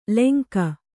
♪ lenka